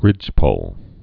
(rĭjpōl)